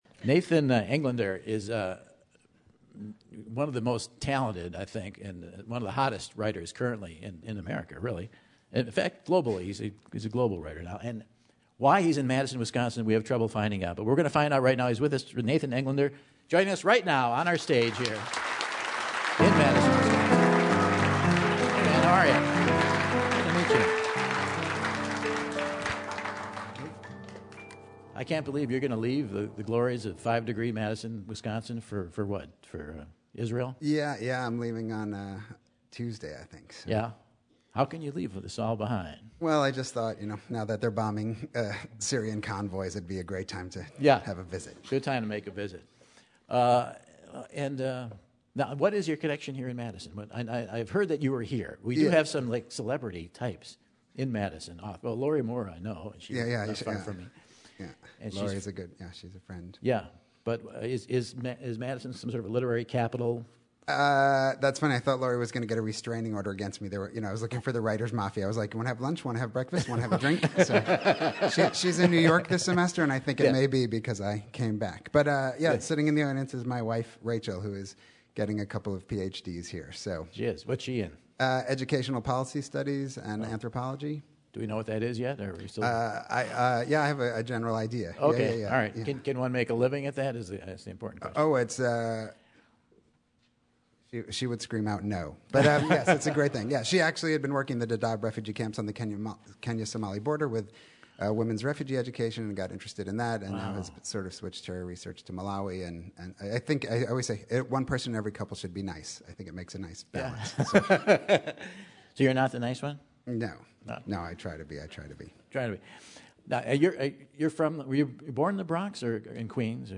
Author, playwright and part-time Madisonian Nathan Englander stops by the Terrace stage to chat with Michael!